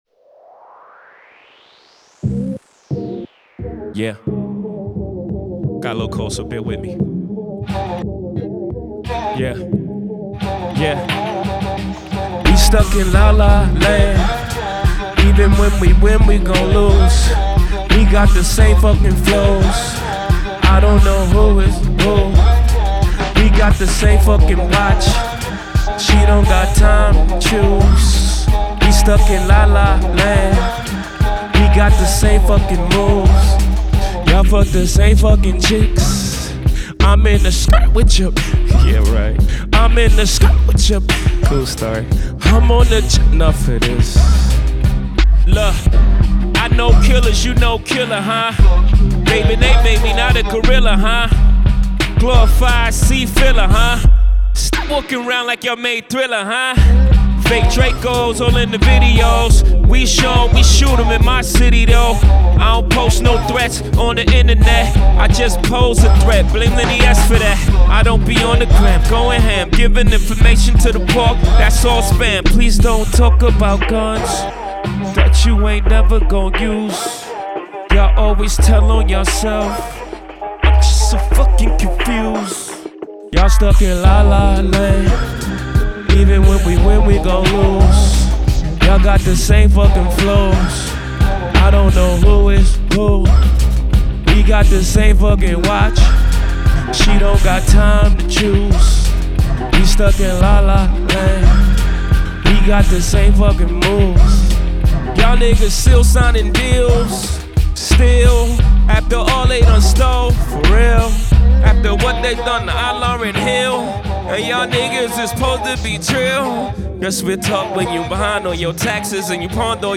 · by · in Hip Hop.